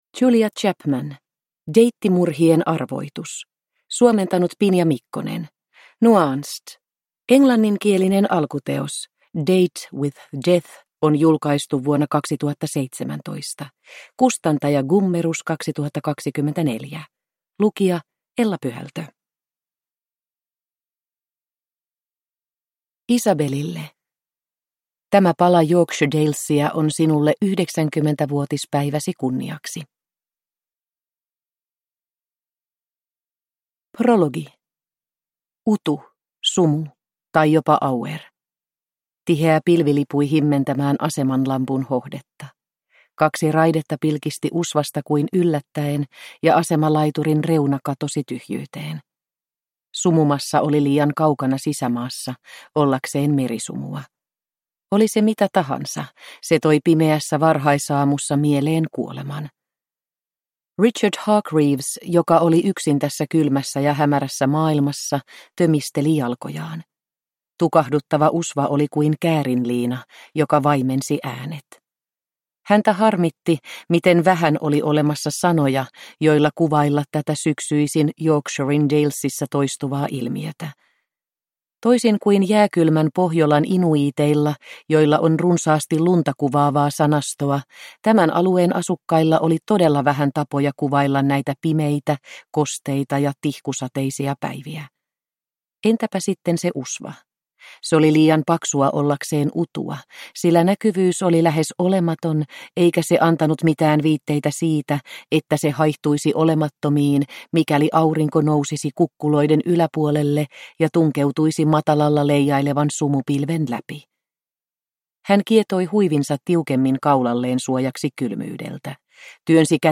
Deittimurhien arvoitus – Ljudbok